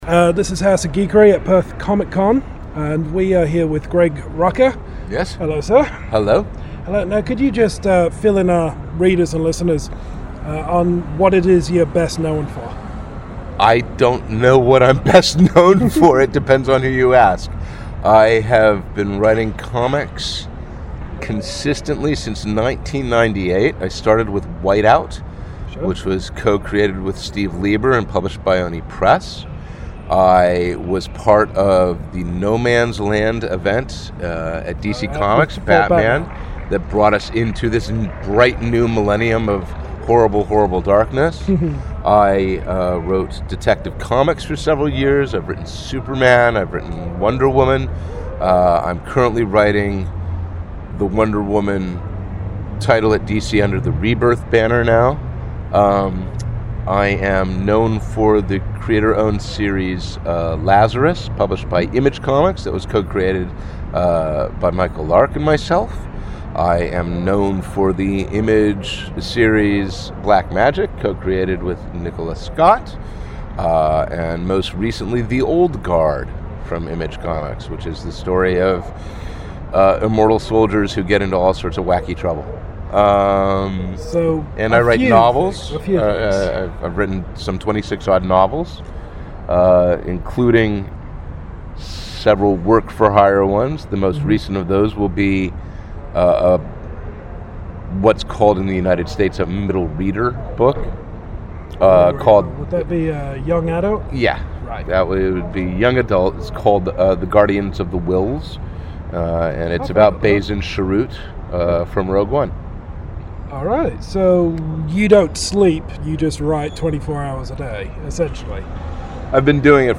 Exclusive Interview with Greg Rucka!
During Oz Comic-Con we were fortunate enough to chat with comic legend Greg Rucka. With many, many iconic titles and a plethora of awards under his belt Rucka is one of the most acclaimed comic writers working today.